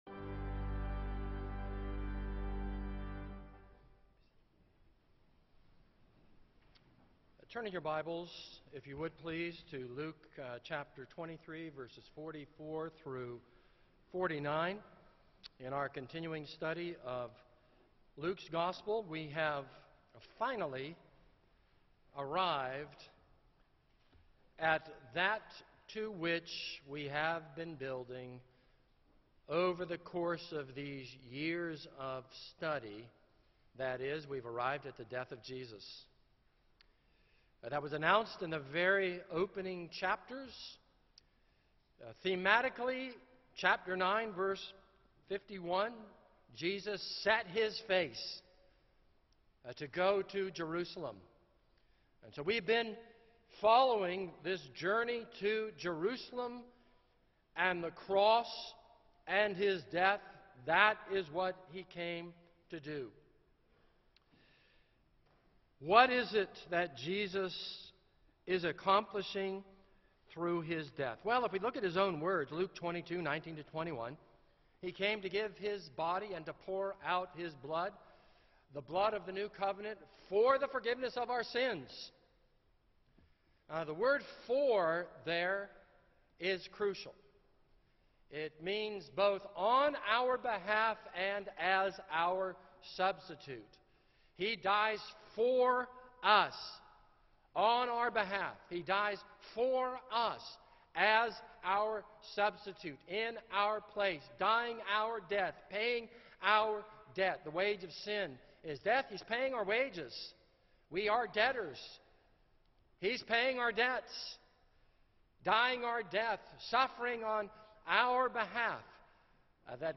This is a sermon on Luke 23:44-49.